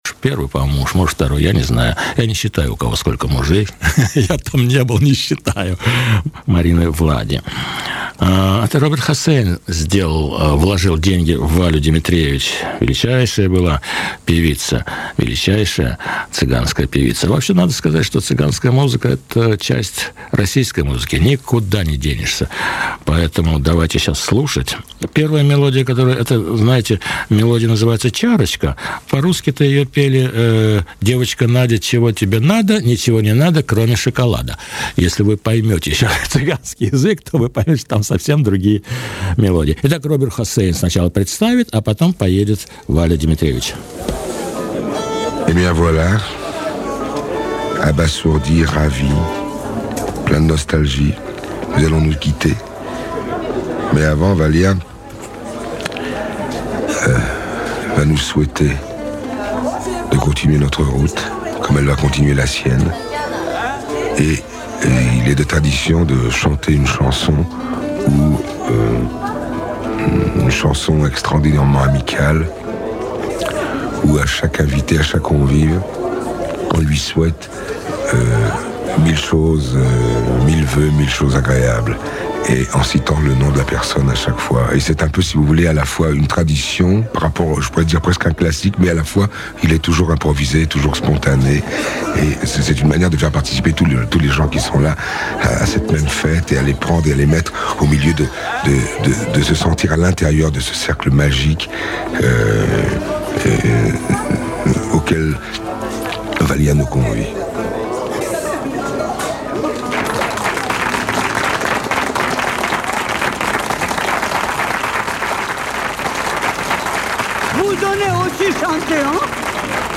Мне удалось найти коротенькую аудиозапись Робера Оссейна представлявшего русских цыган.